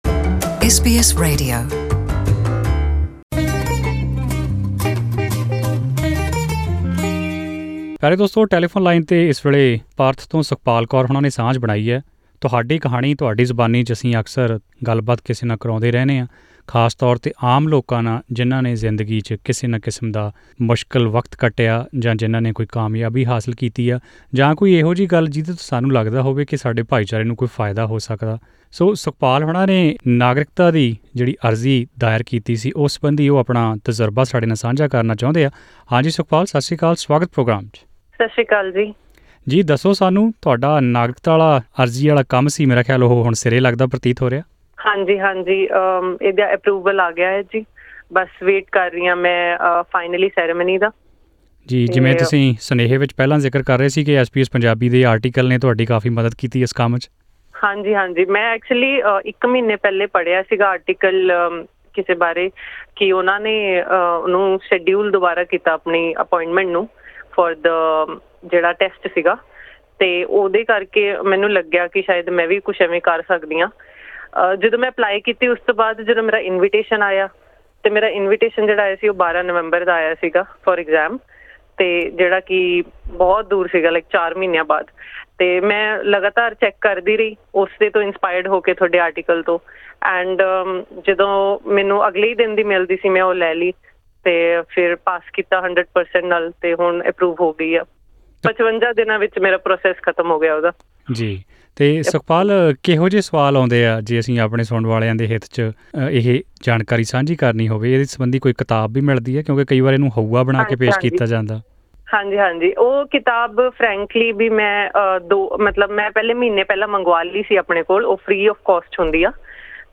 Listen to this conversation how she was able to speed up this process.